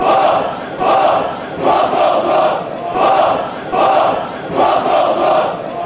Звуки болельщиков
Крики поддержки для атакующей команды